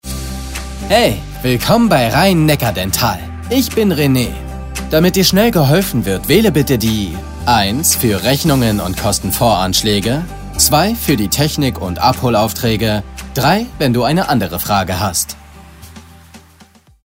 Telefonansage junge Stimme
Seine Stimme klingt jung, cool, frisch und locker.
IVR Ansage Rhein-Neckar Dental